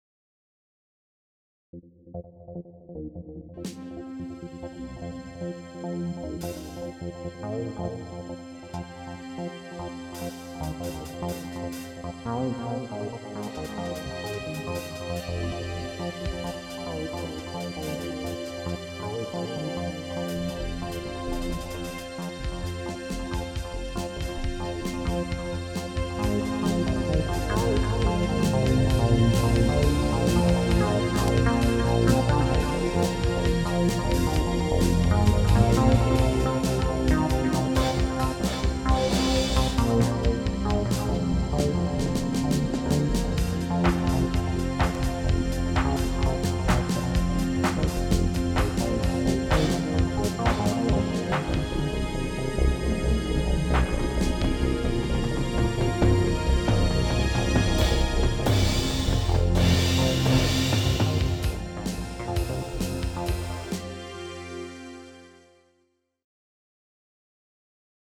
The next is a combination of softsynth sounds with LADSPA effects to do amplifier and speaker simulation, and reverb and tapped delay IIRC with some mixing